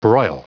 Prononciation du mot broil en anglais (fichier audio)
Prononciation du mot : broil